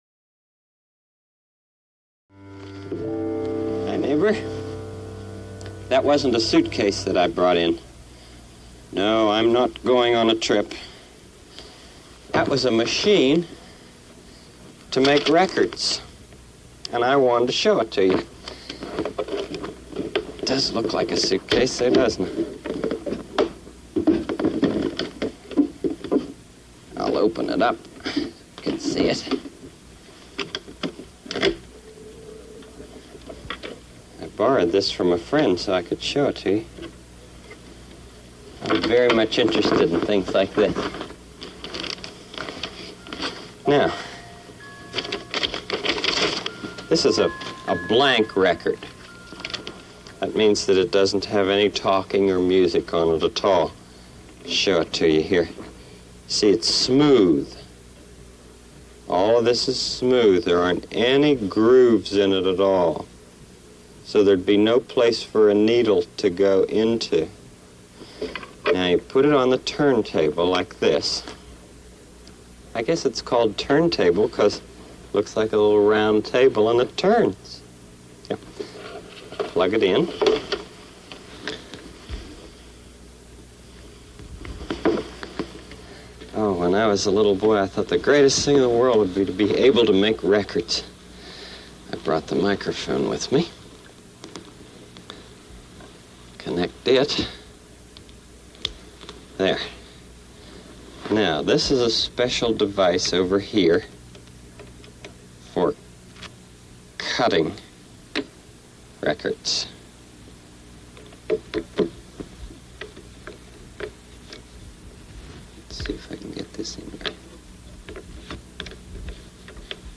The listener is not addressed and they are not in the room.